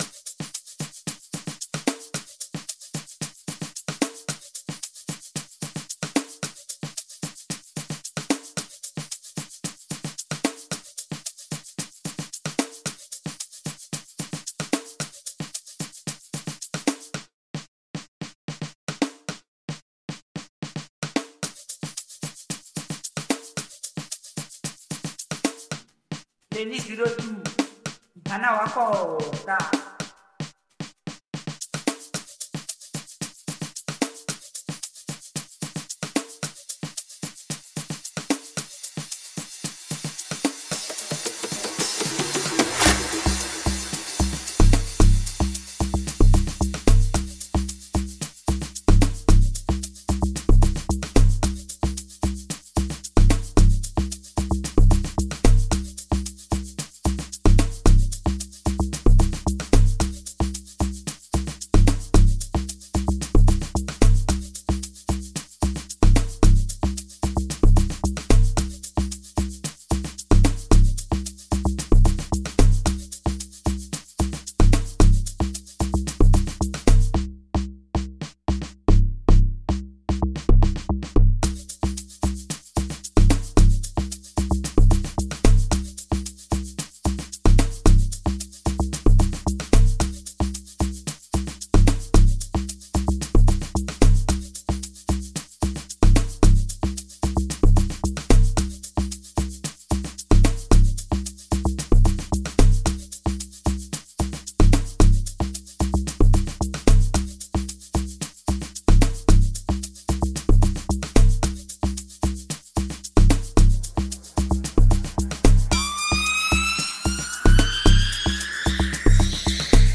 05:12 Genre : Amapiano Size